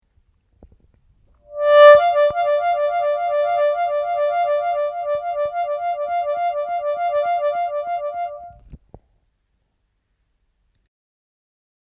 Играть трель на отверстиях -4-5, а также на отверстиях -3 -4.
Трель -4-5